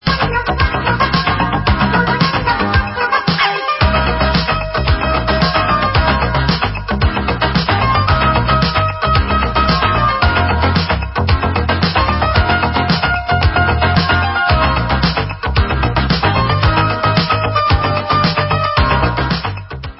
sledovat novinky v oddělení Disco